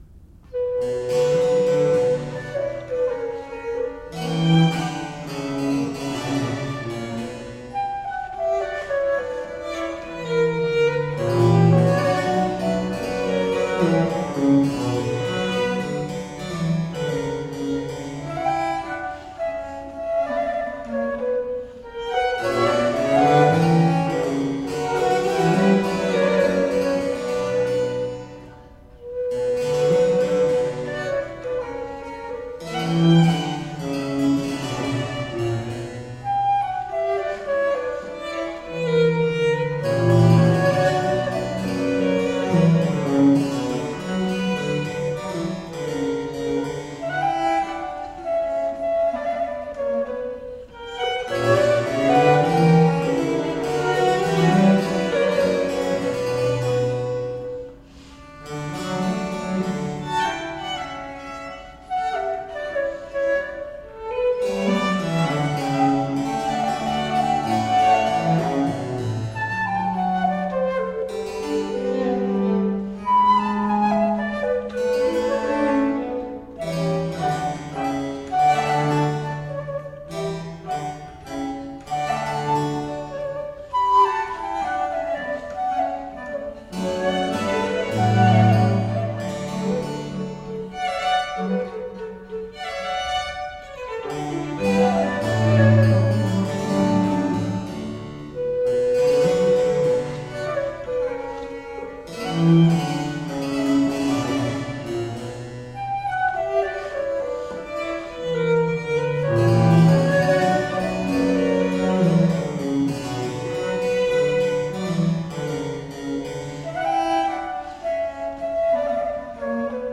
Rare and extraordinary music of the baroque.
lightly elegant dance music
violinist
flutist
harpsichord
violoncello